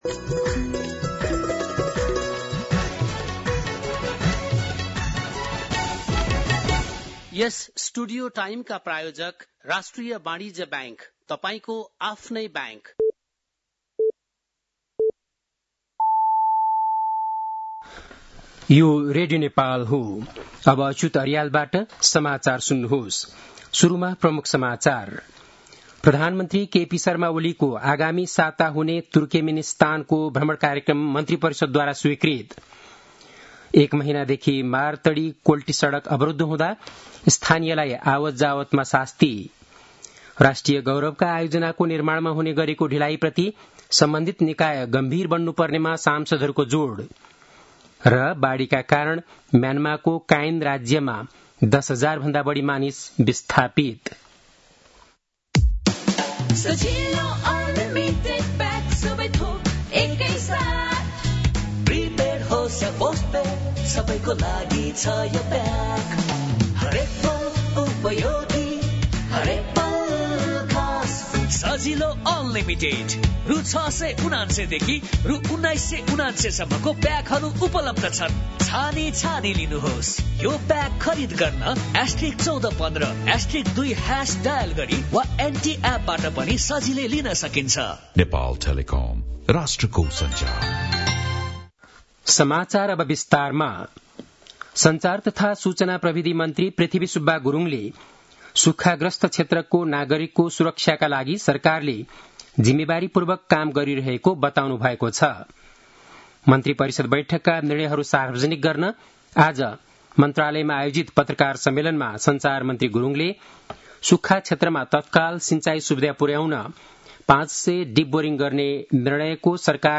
बेलुकी ७ बजेको नेपाली समाचार : १५ साउन , २०८२
7-pm-nepali-news-1-1.mp3